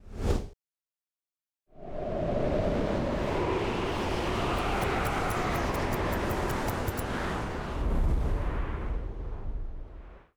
SFX_Schlappentornado_02.wav